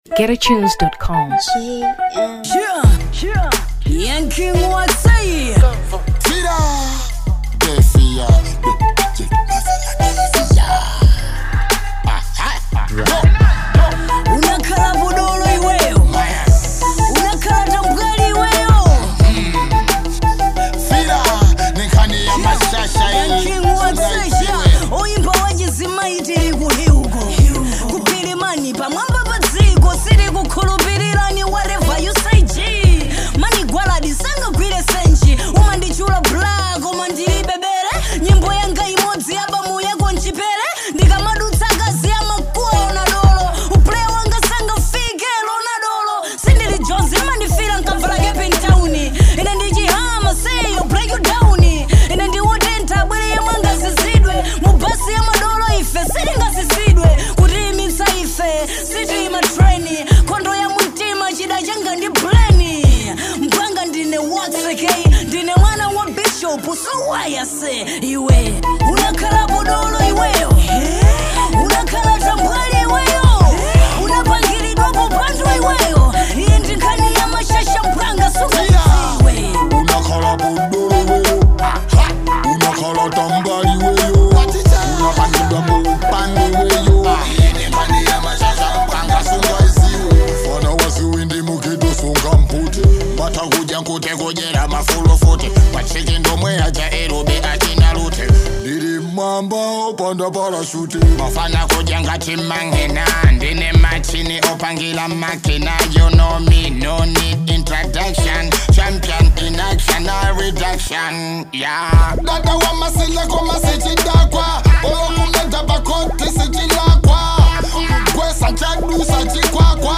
Afro Hip-Hop 2023 Malawi